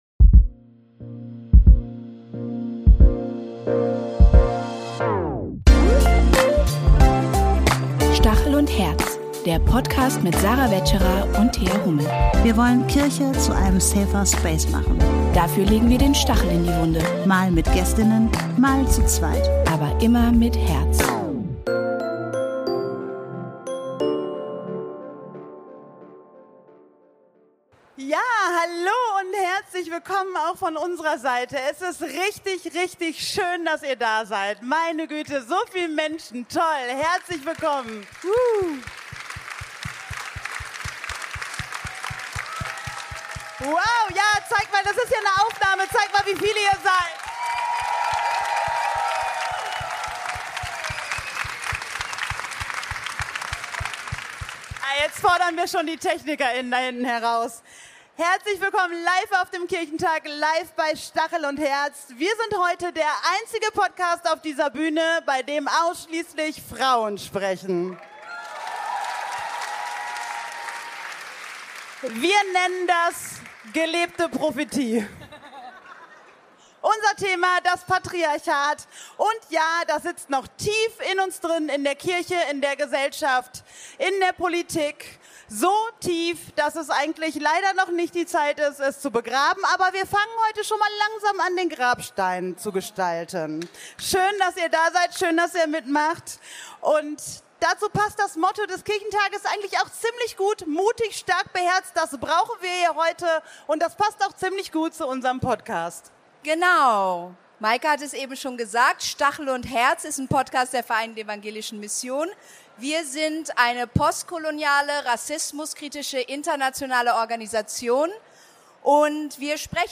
In dieser Livefolge von Stachel & Herz (02.05. in Hannover beim DEKT)